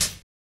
Closed Hats
HiHat (Gas Drawls).wav